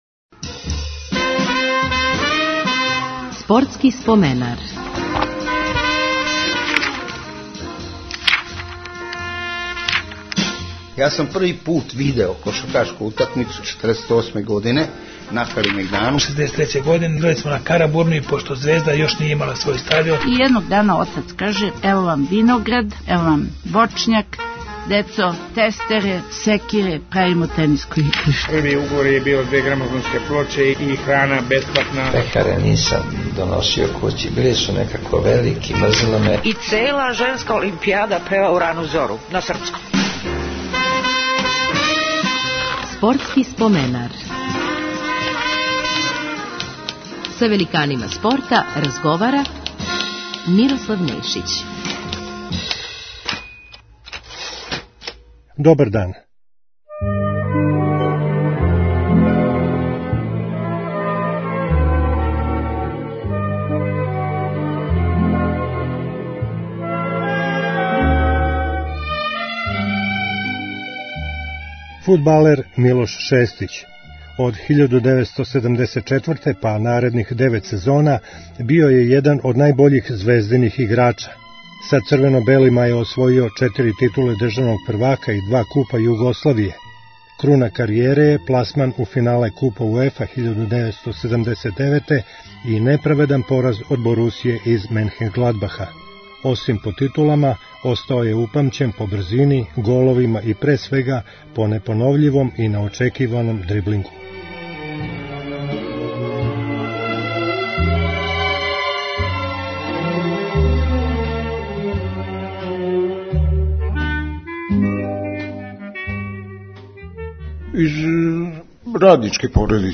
Гост 347. емисије је фудбалер Милош Шестић.